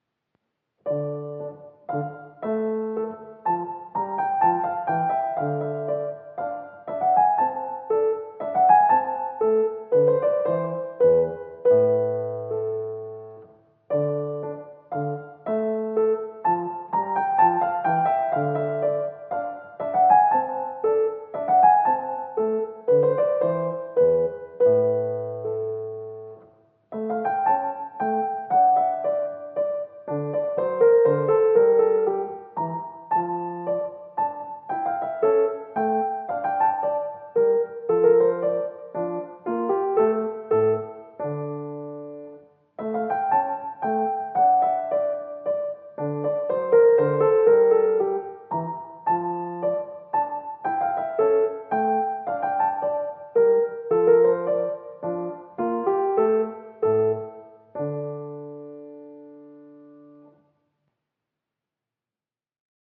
mp3Seixas, Carlos de, Sonata No. 30 in D minor, mvt.
Minuet